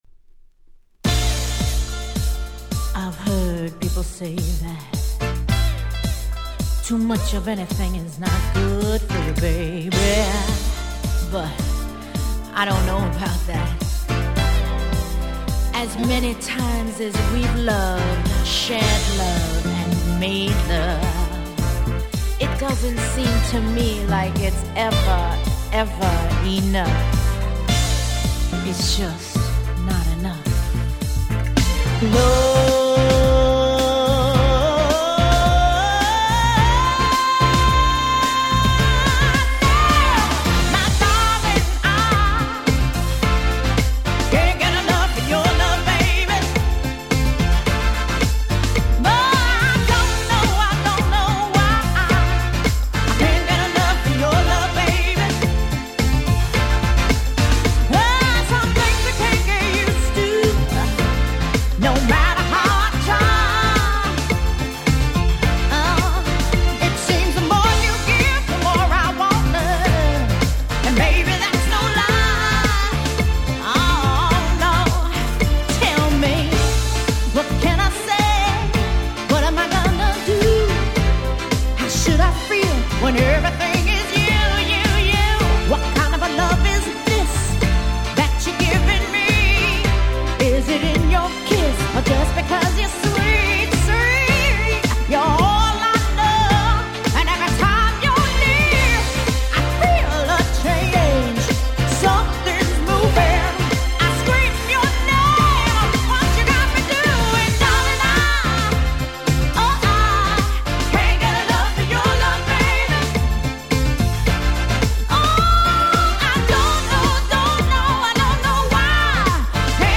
93' Nice Cover R&B♪
これがまたキャッチーで最高の出来です！